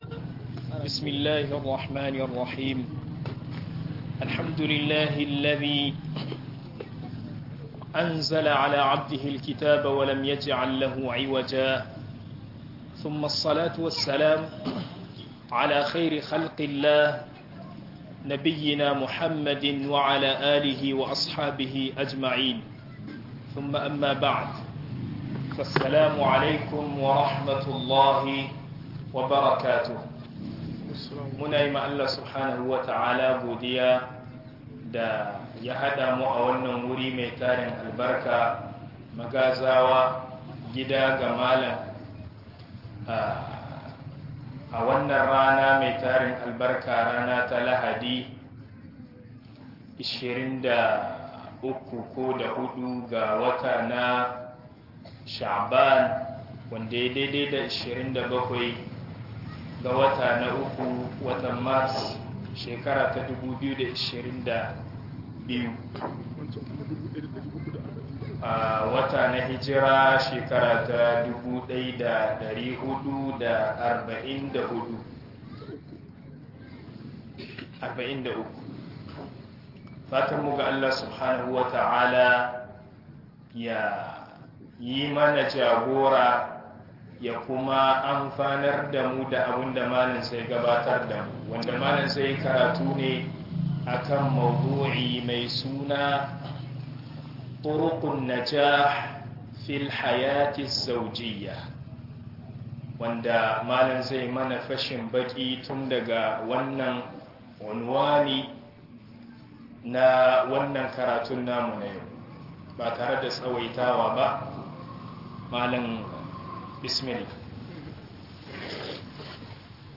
Aure da Rayuwar ma'aurata - MUHADARA